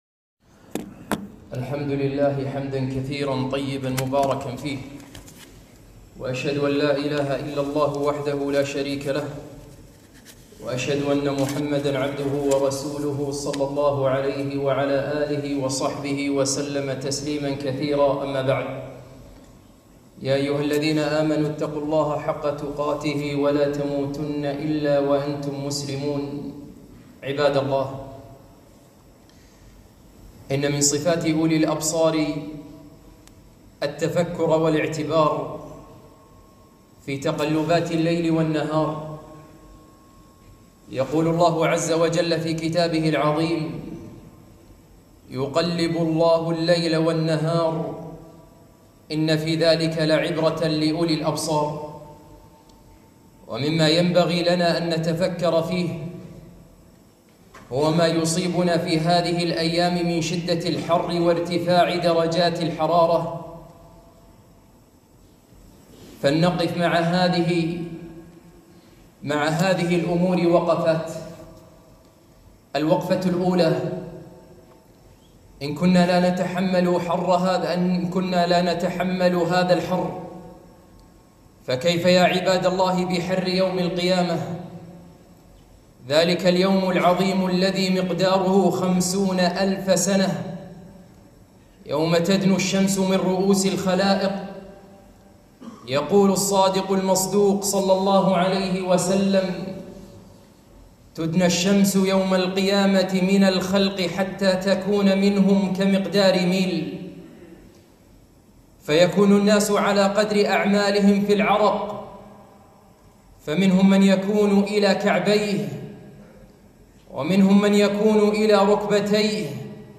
خطبة - حر الصيف